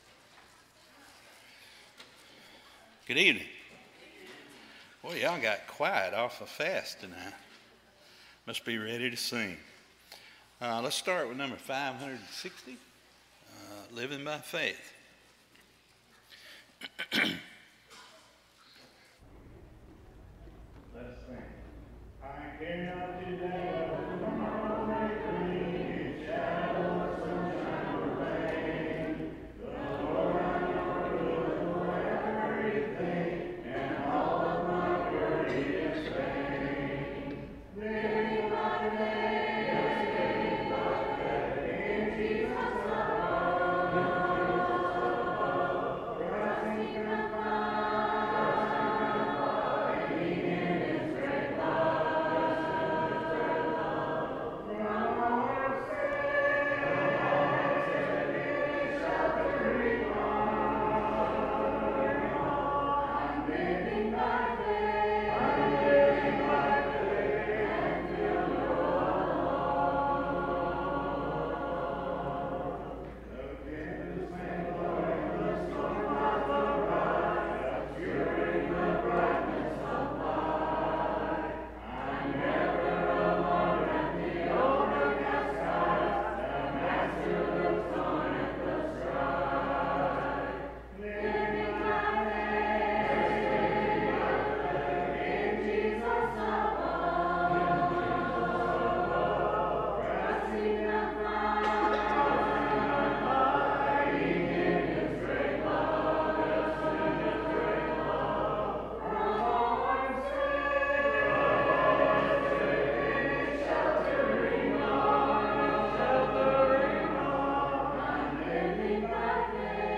(Romans 8:6) Series: Sunday PM Service